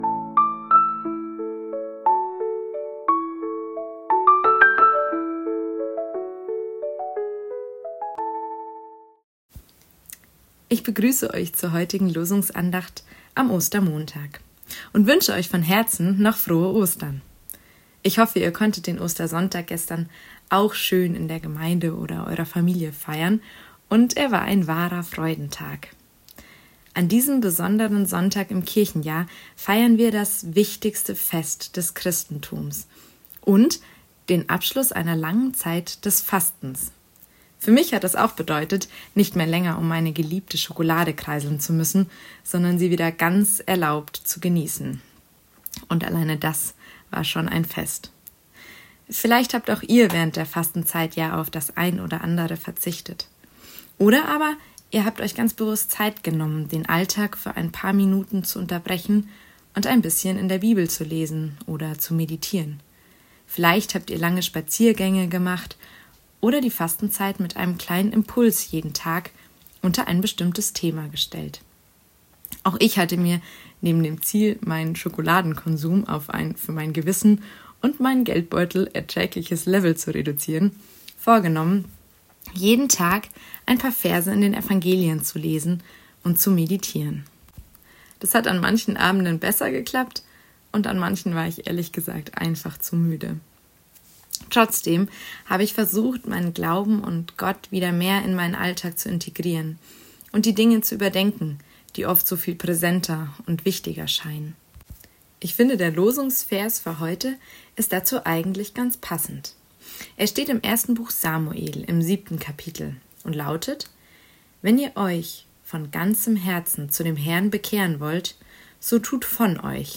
Losungsandacht für Montag, 21.04.2025